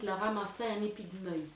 Falleron ( Plus d'informations sur Wikipedia ) Vendée
Langue Maraîchin
Catégorie Locution